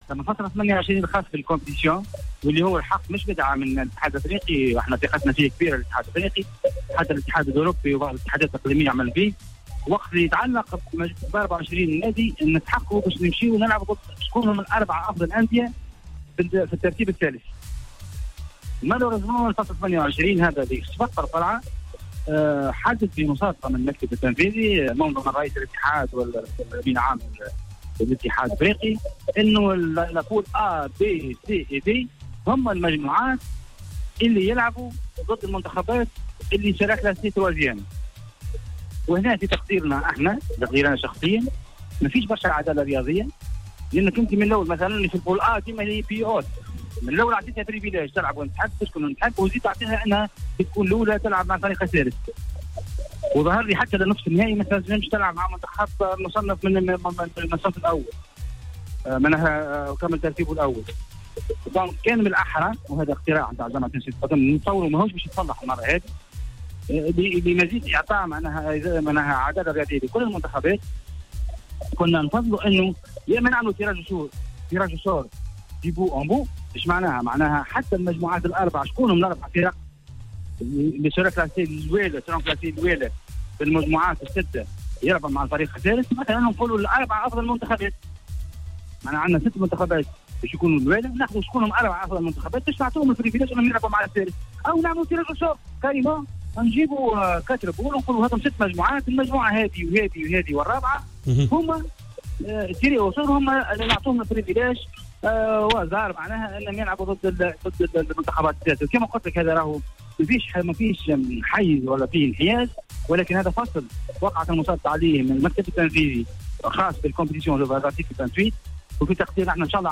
أشار رئيس الجامعة التونسية لكرة القدم الدكتور وديع الجريء لدى تدخله في حصة "Planète Sport" أن بعض الفصول القانونية المنظمة لمسابقة كأس إفريقيا للأمم في نسختها الجديدة ب 24 منتخبا تحتاج لإعادة النظر.